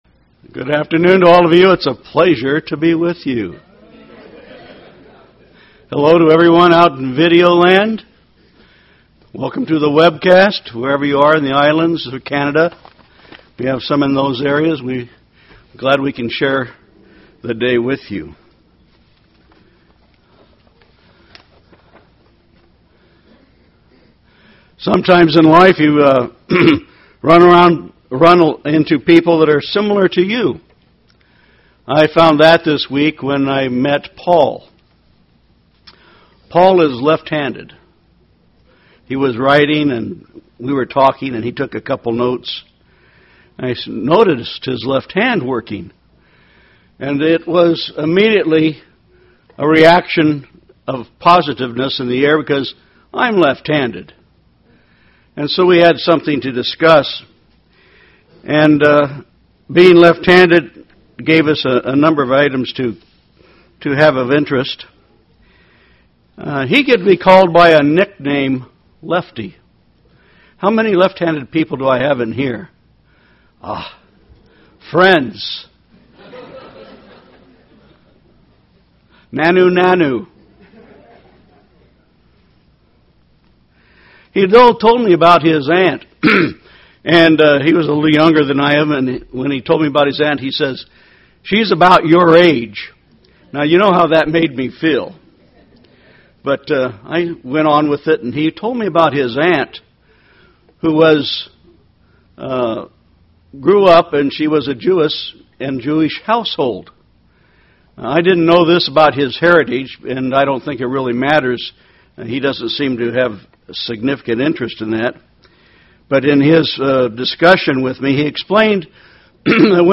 Given in Tampa, FL St. Petersburg, FL
UCG Sermon Studying the bible?